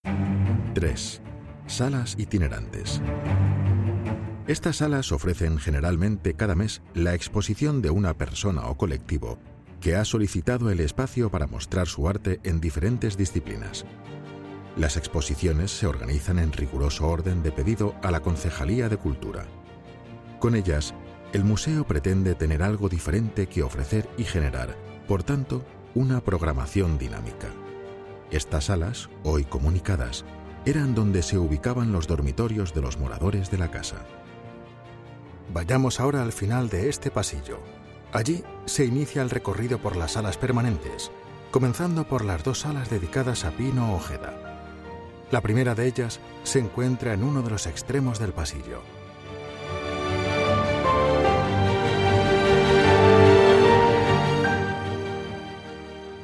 audioguia_Museo_Municipal_Arucas_ES_1_03.mp3